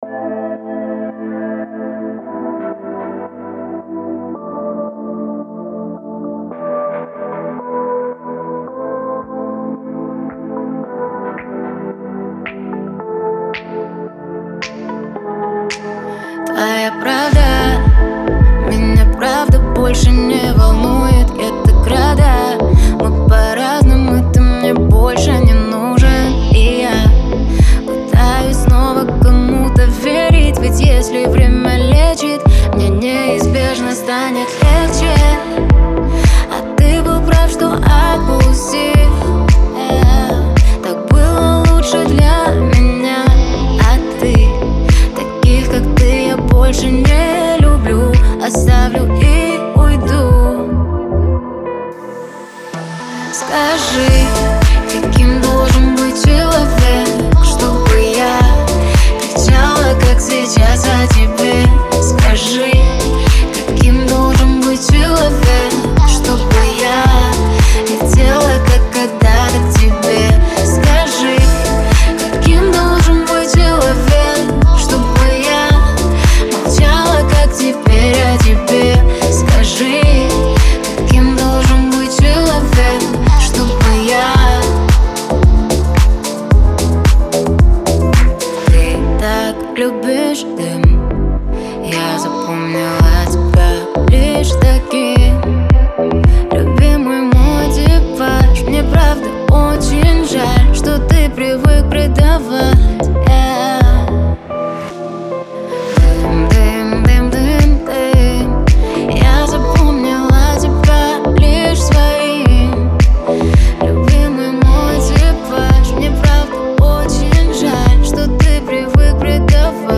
который сочетает в себе элементы поп и R&B